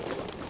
Gear Rustle Redone
tac_gear_23.ogg